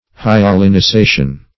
hyalinisation - definition of hyalinisation - synonyms, pronunciation, spelling from Free Dictionary